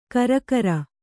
♪ karakara